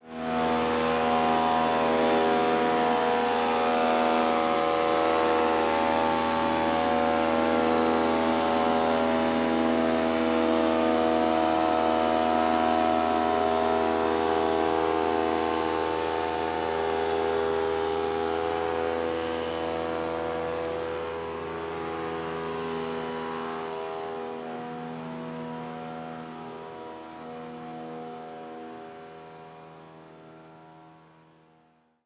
FreeGardenSFX_LeafBlower.wav